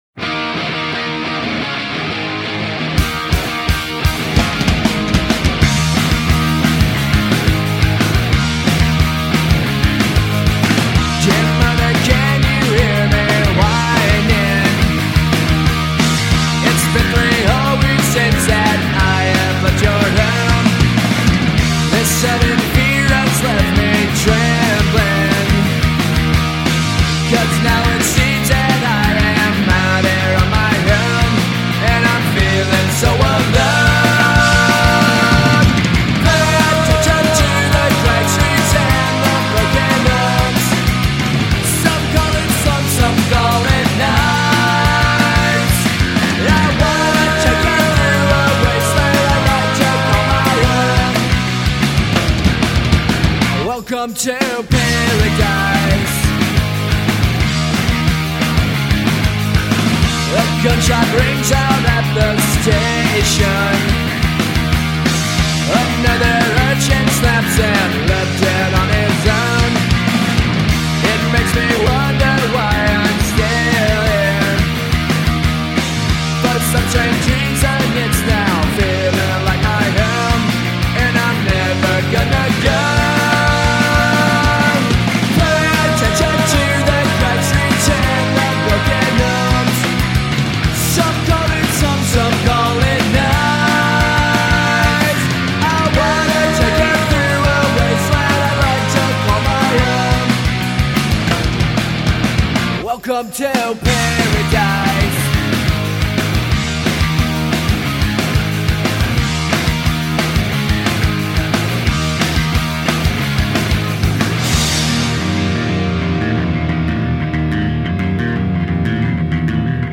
They are the greatest punk rock band in the UNIVERSE!